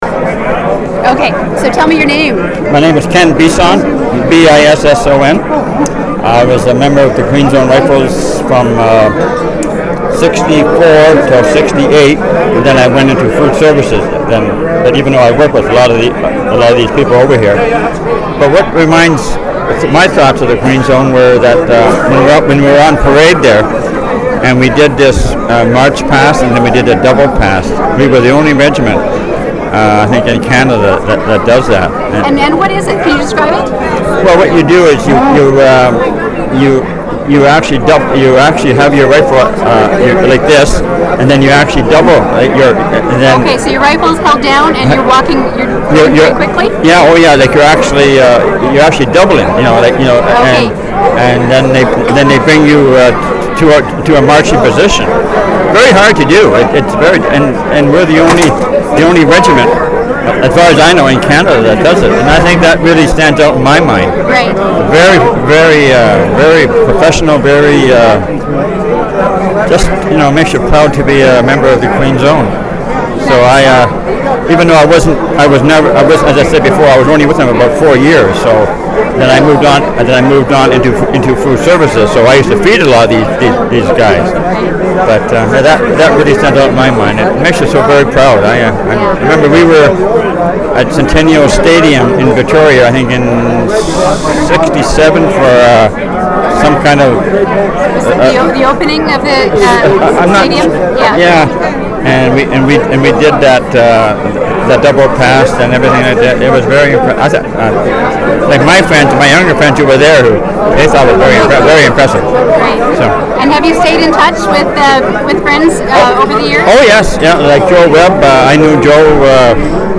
• Interview took place during the Queen's Own Rifles of Canada Vancouver Island Branch 150th Anniversary Celebration.
• Canadian Military Oral History Collection